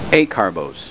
Pronunciation
(AY car bose)